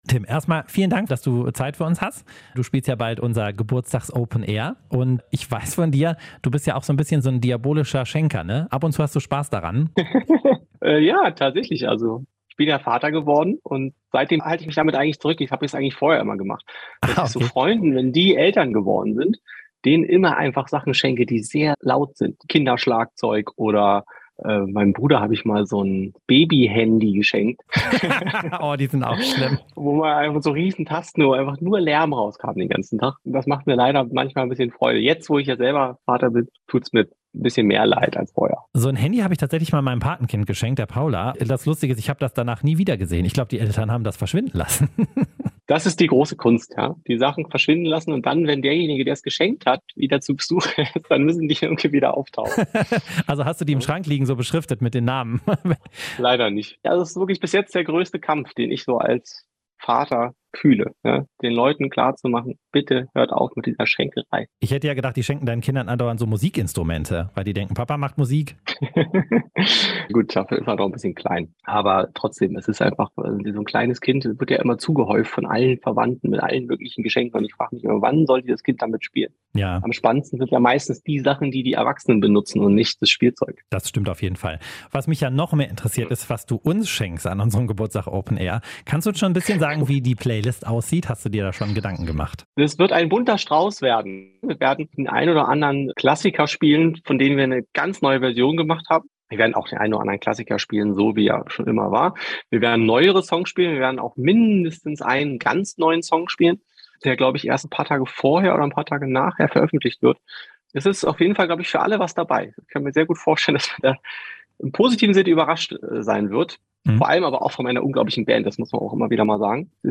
Vor seinem Auftritt beim Radio RSG Open Air am 17. Juni waren wir auch mit Tim Bendzko zum ausführlichen Interview verabredet.
tim_bendzko_interview_komplett.mp3